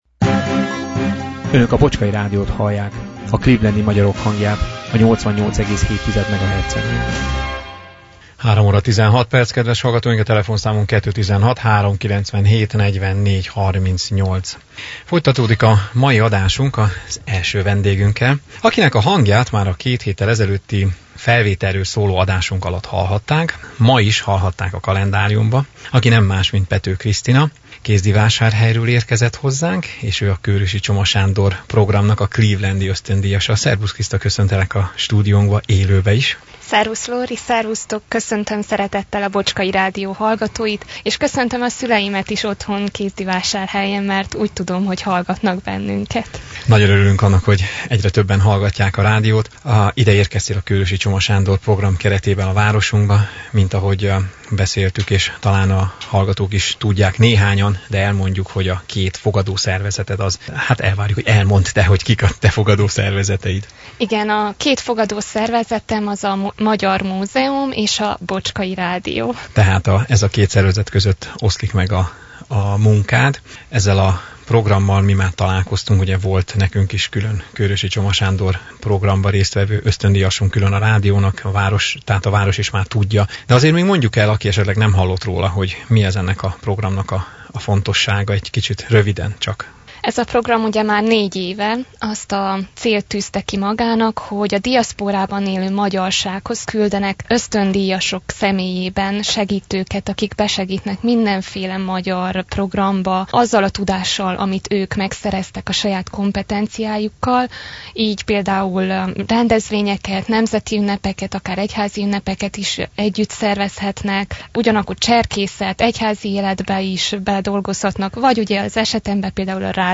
Reklám A vele készült teljes interjút az alábbi linken hallgathatják meg: https